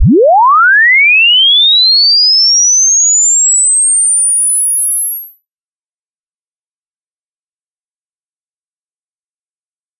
正弦扫频 " 0赫兹至22500k赫兹，10秒内完成
描述：在Audacity产生的正弦波扫描从0赫兹到22.5千赫兹。